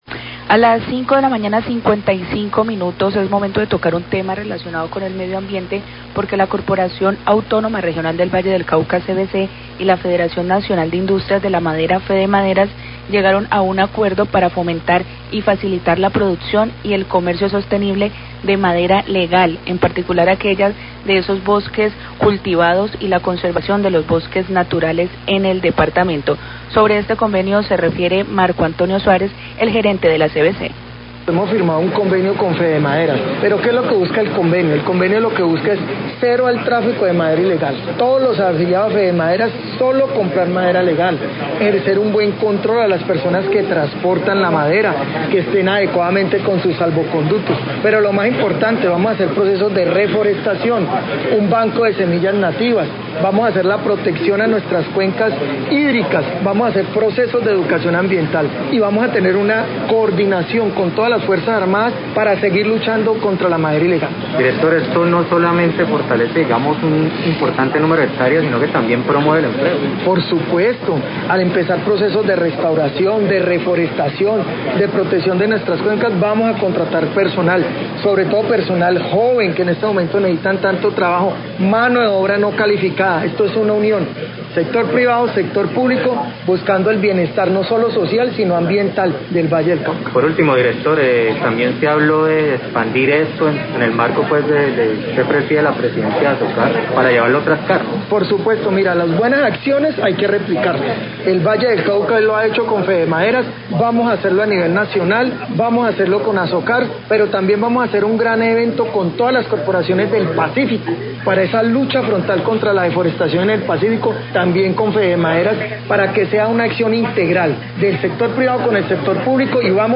Radio
La CVC y la Federación Nacional de la Industria de la Madera, Fedemaderas, firman convenio para la producción y comercialización sostenible de maderas. Declaraciones de Marco Antonio Suárez, director generald e la CVC.